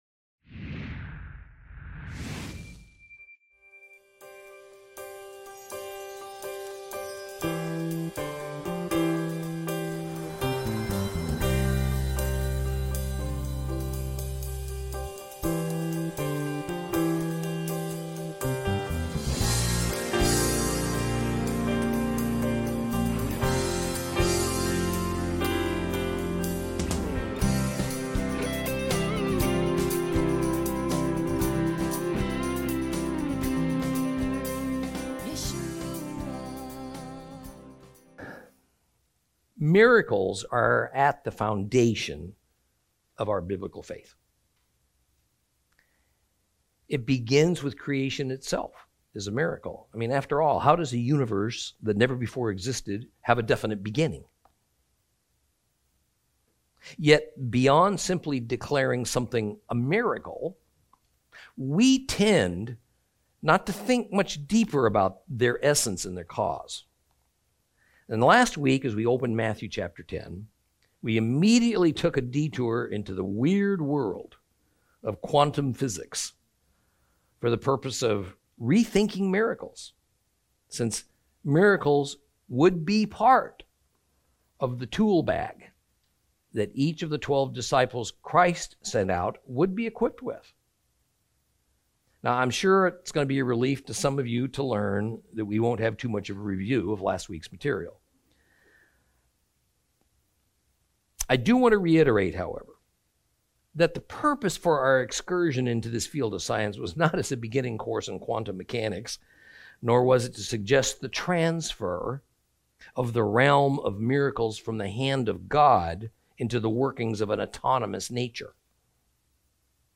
Lesson 35 Ch10